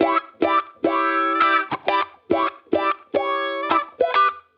Index of /musicradar/sampled-funk-soul-samples/105bpm/Guitar
SSF_StratGuitarProc1_105E.wav